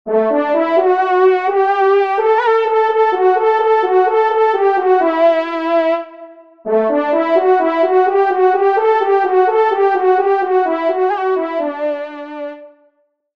Fanfare de personnalité
Genre :  Musique de Vènerie